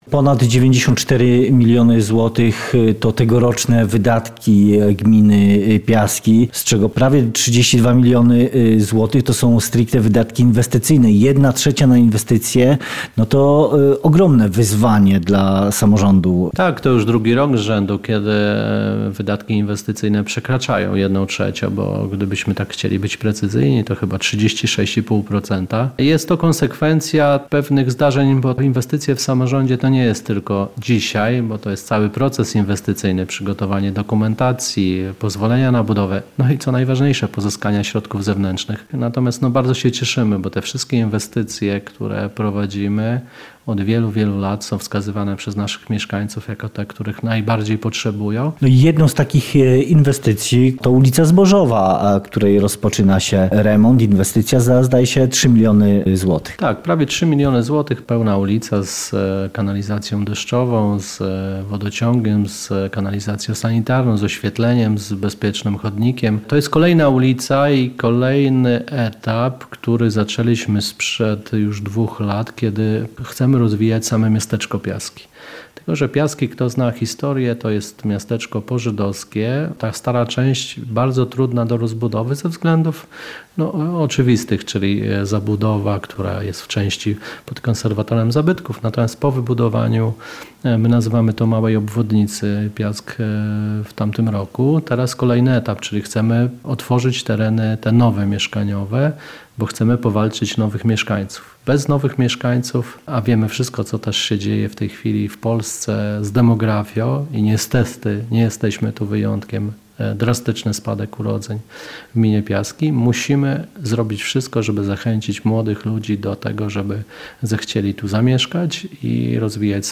z burmistrzem Piask Michałem Cholewą rozmawia